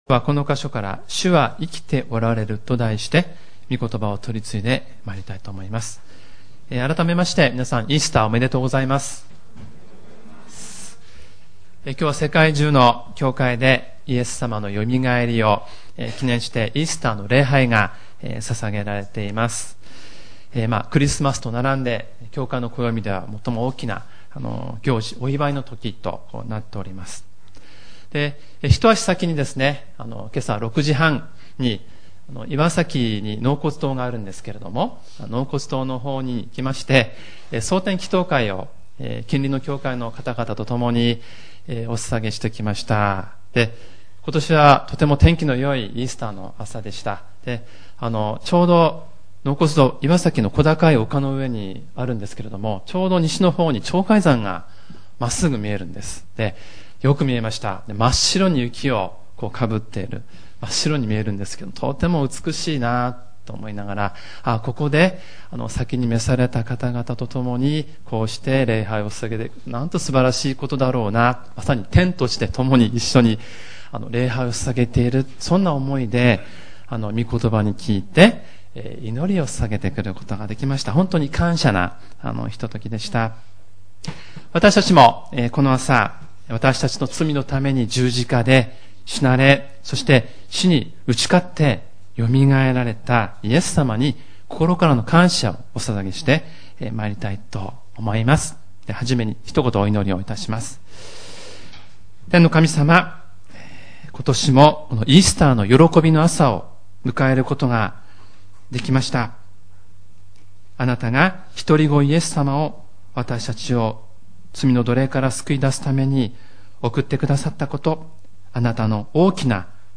イースター礼拝メッセージ